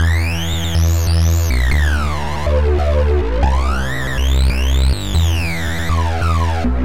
Tag: 14 bpm Dubstep Loops Synth Loops 1.16 MB wav Key : Unknown